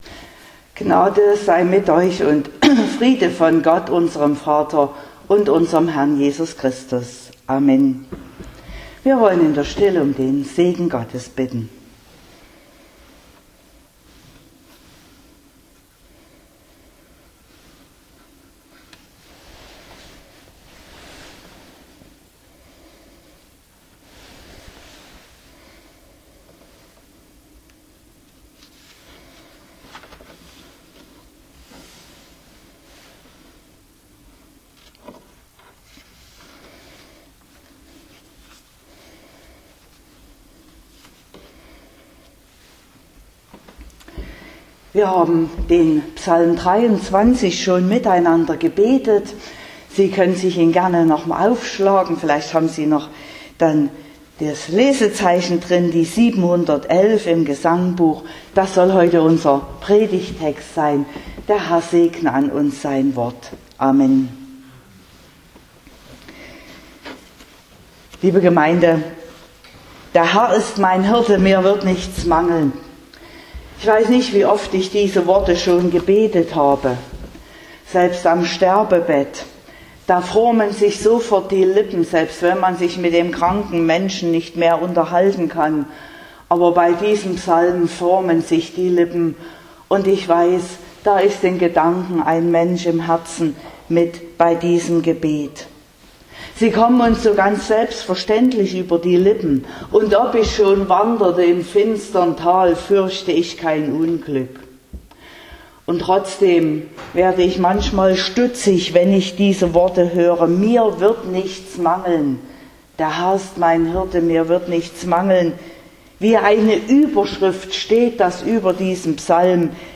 01.05.2022 – Gottesdienst
Predigt (Audio): 2022-05-01_Alles_was_du_brauchst.mp3 (32,3 MB)